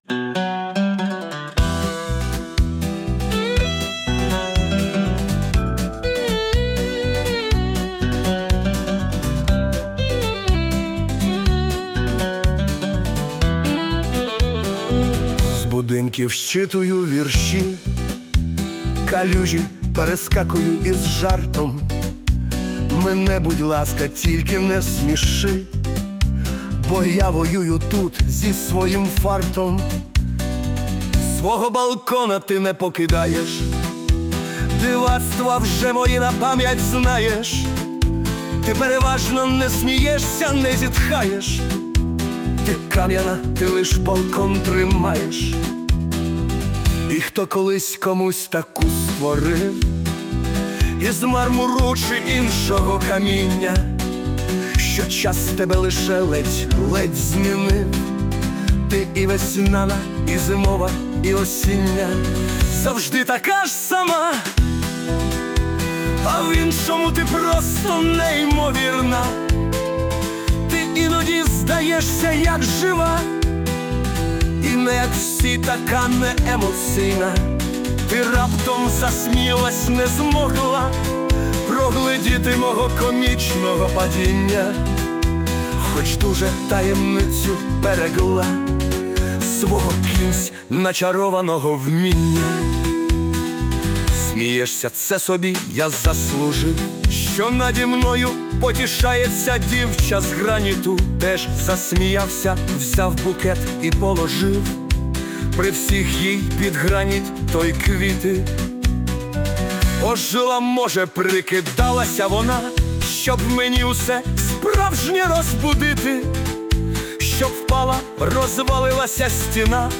Музичний супровід створено з допомогою ШІ
СТИЛЬОВІ ЖАНРИ: Ліричний